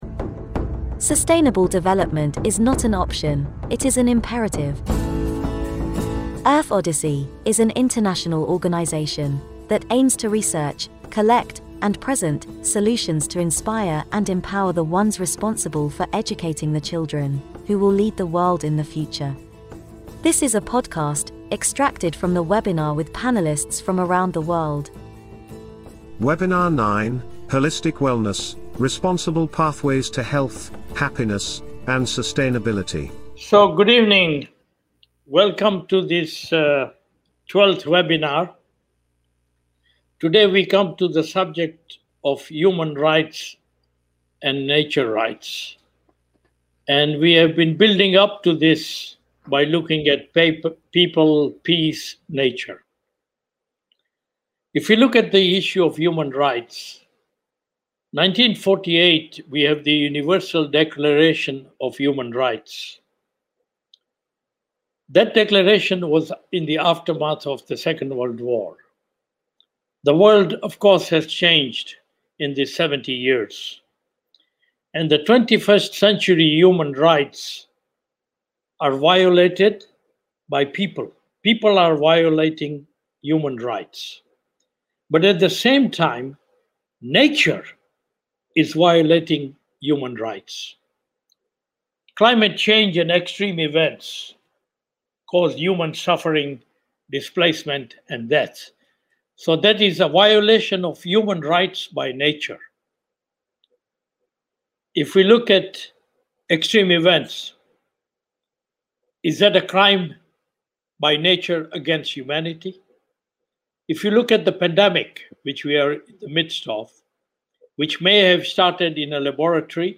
Join us in this Webinar to celebrate International Day of Biodiversity, with Rights of Mother Earth, End Ecocide Sweden, and Earth Odyssey.